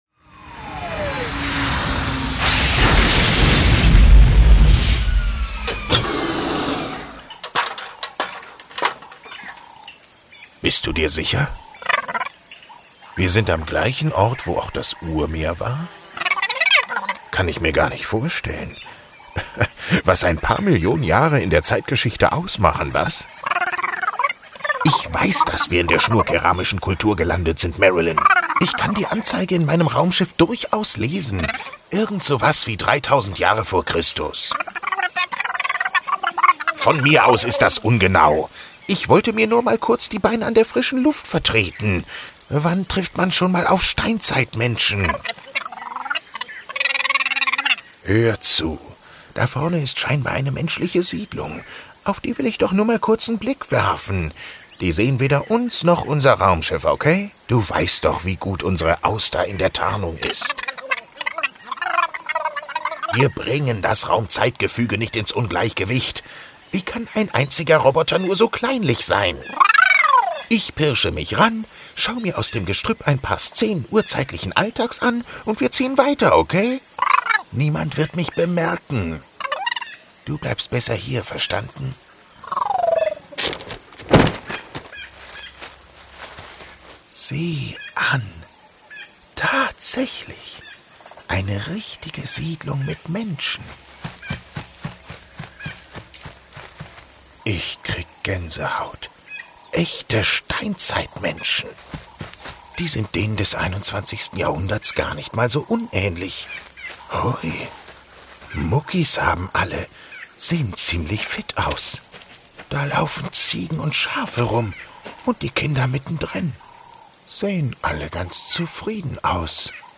Die anschließend eingearbeitete Soundkulisse und die entsprechende Musik schaffen schließlich ein atmosphärisch dichtes Hörspiel – maßgeschneidert zur historischen oder aktuellen Stadtgeschichte.